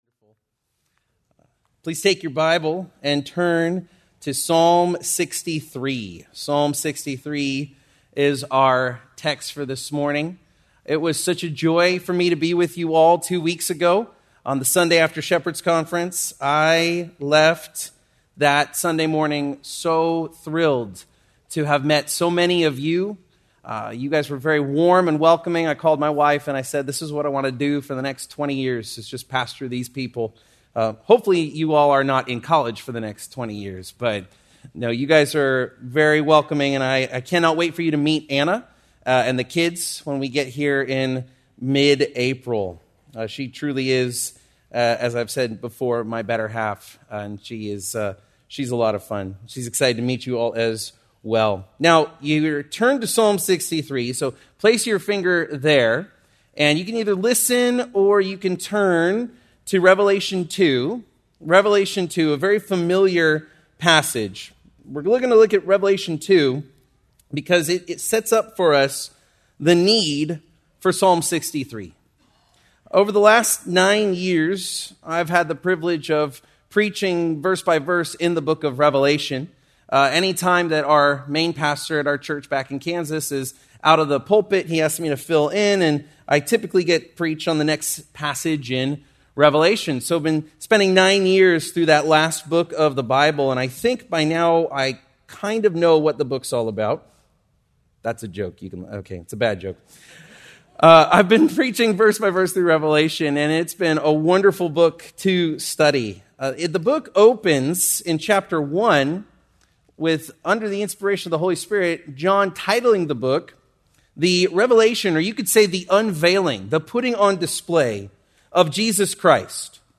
March 22, 2026 - Sermon